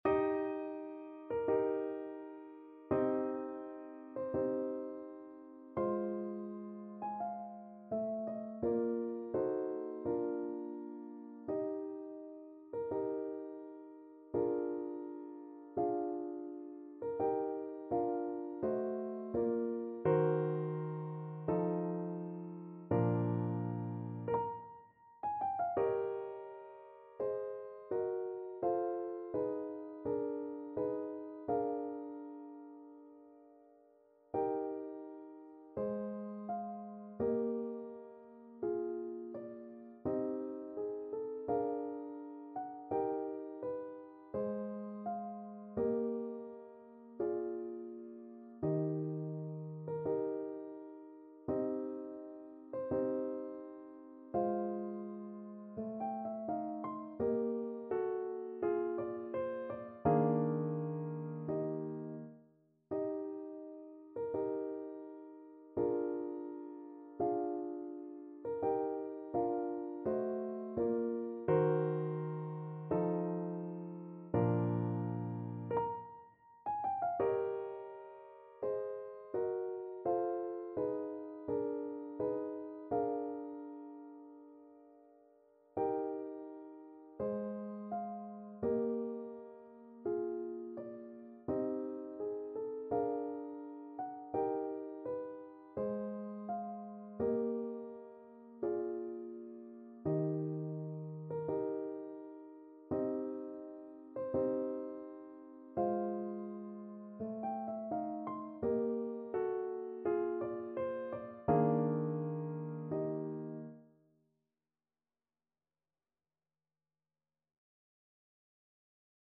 Play (or use space bar on your keyboard) Pause Music Playalong - Piano Accompaniment Playalong Band Accompaniment not yet available transpose reset tempo print settings full screen
Eb major (Sounding Pitch) (View more Eb major Music for Violin )
2/4 (View more 2/4 Music)
~ = 42 Sehr langsam
Classical (View more Classical Violin Music)